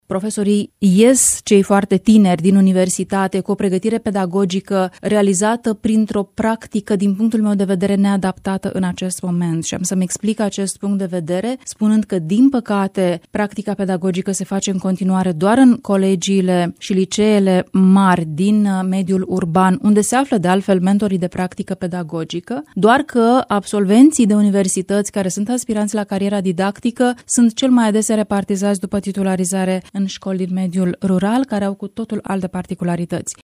Adevărata urgență a sistemului de învățământ rămâne formarea cadrelor didactice, a declarat în emisiunea Dezbaterea Zilei,  consilierul de stat în cancelaria prim-ministrului,  Luciana Antoci.